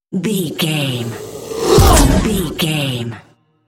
Creature sci fi shot appear
Sound Effects
Atonal
scary
ominous
dark
eerie
whoosh